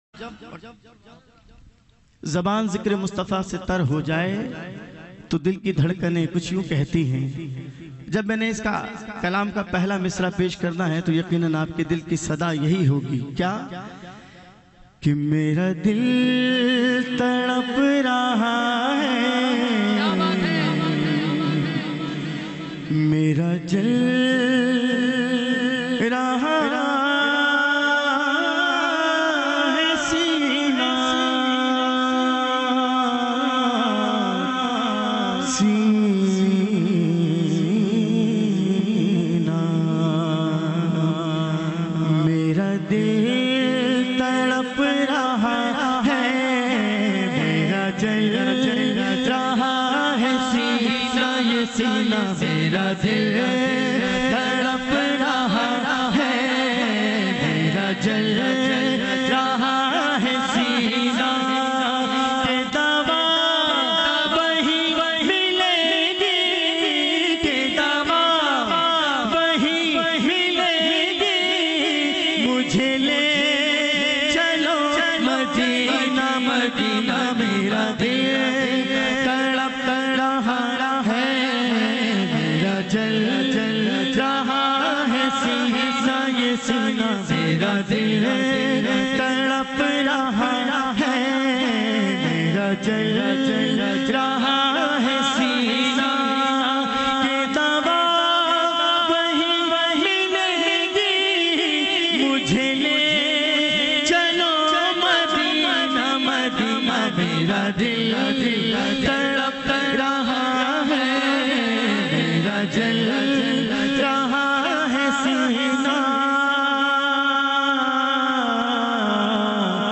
Heart-Touching Voice